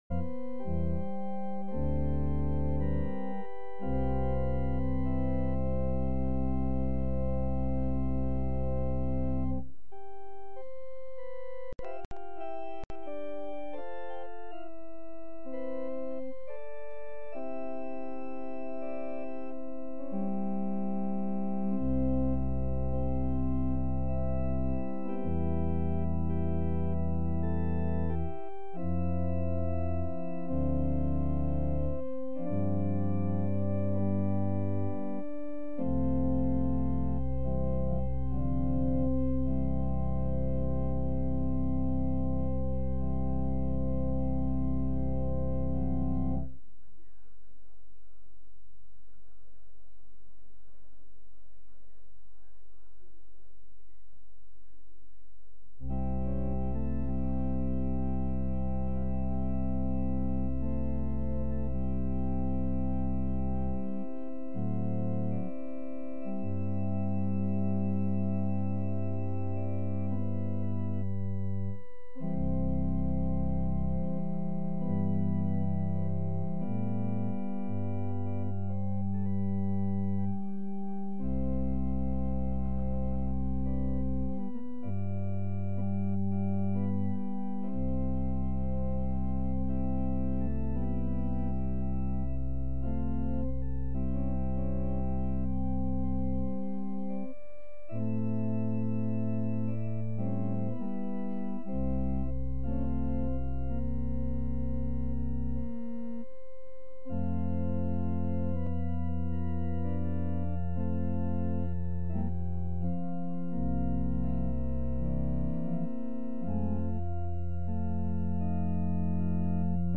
Sermons | Grace Lutheran Church
Sunday-Service-7-17-22.mp3